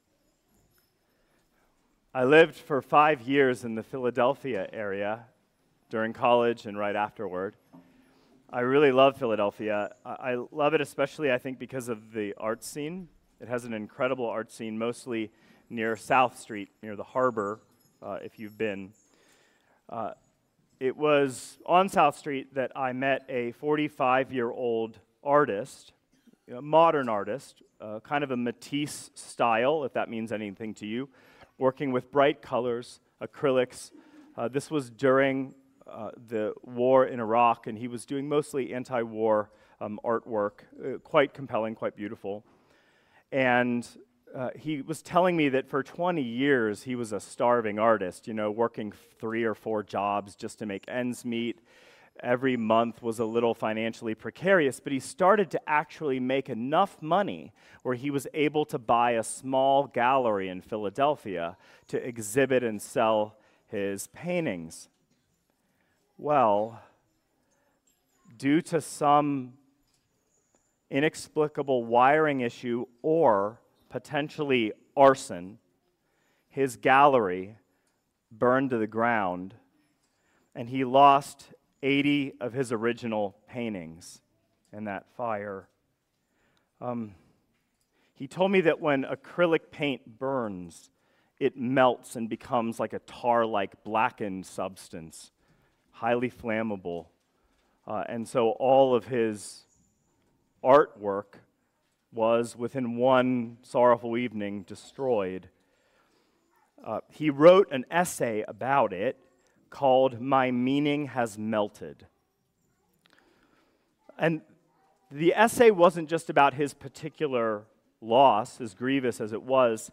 2026 Sermons